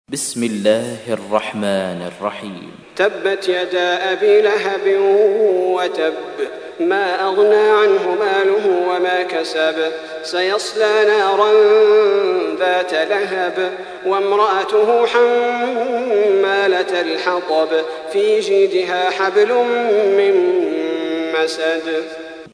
تحميل : 111. سورة المسد / القارئ صلاح البدير / القرآن الكريم / موقع يا حسين